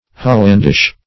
Hollandish \Hol"land*ish\, a. Relating to Holland; Dutch.